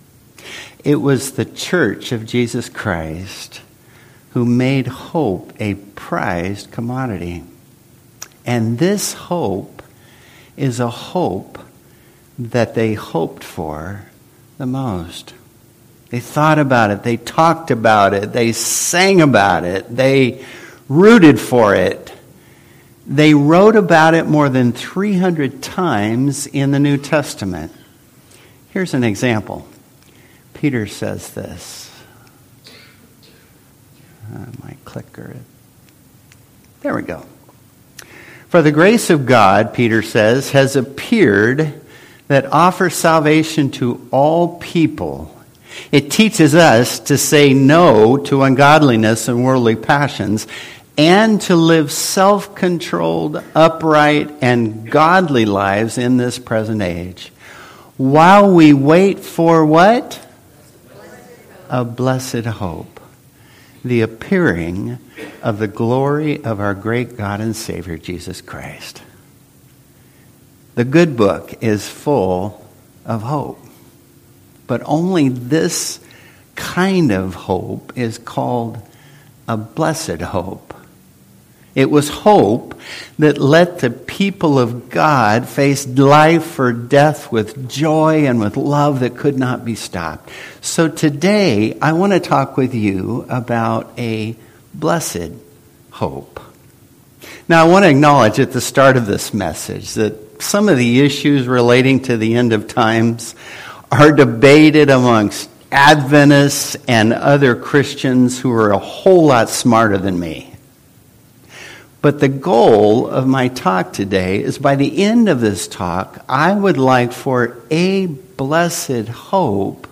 Service Type: Worship Service Topics: Advent , end time , Revelation